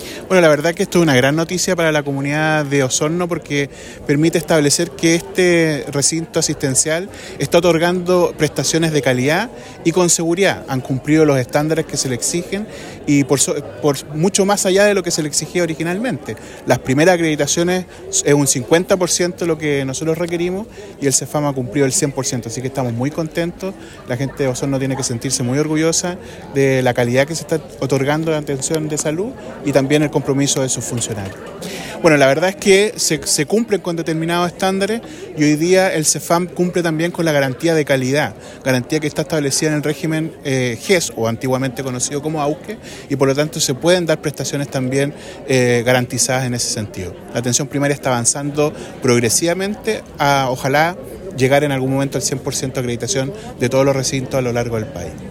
La ceremonia de entrega del reconocimiento estuvo encabezada por el superintendente de Salud, Víctor Torres, quien destacó el compromiso y la calidad del trabajo realizado por el equipo del CESFAM Pampa Alegre, indicando que este recinto ha demostrado no solo cumplir, sino exceder los estándares requeridos, lo que permite entregar atenciones bajo las Garantías Explícitas de Salud con un alto nivel de eficiencia y profesionalismo.